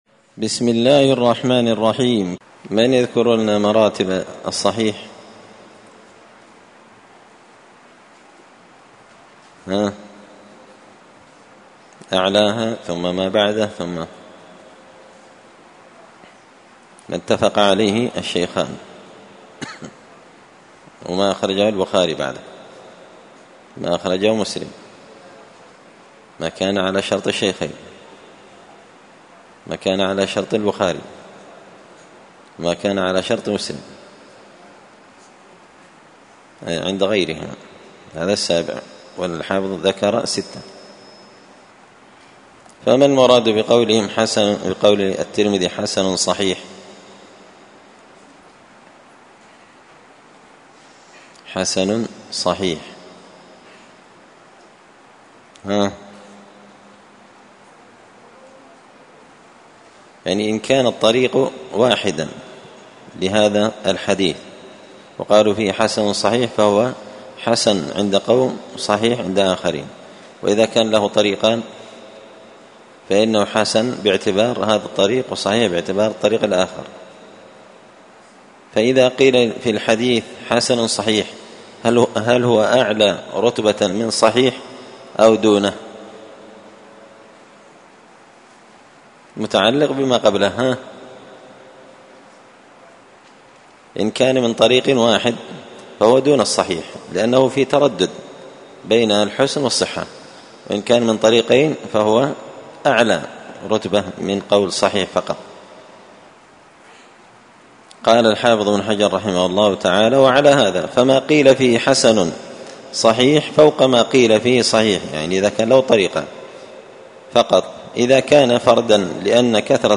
تعليق وتدريس الشيخ الفاضل:
10الدرس-العاشر-من-كتاب-نزهة-النظر-للحافظ-ابن-حجر.mp3